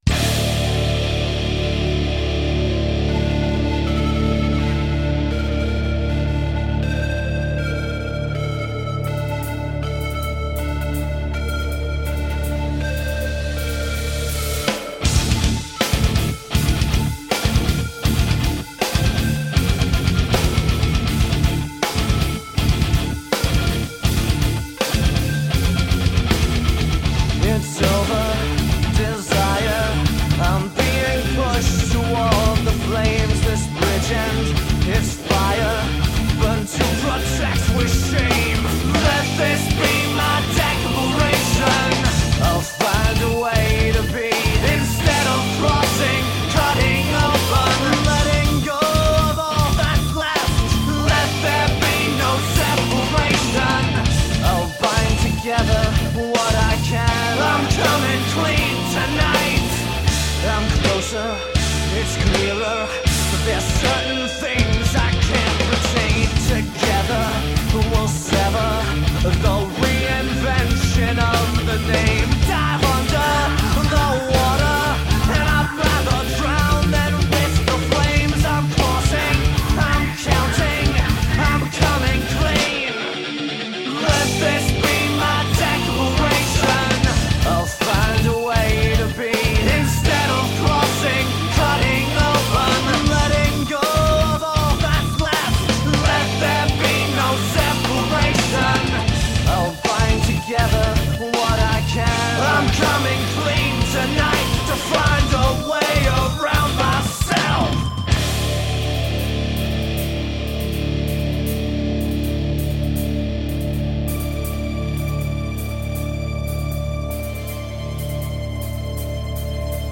Melodic modern hard rock.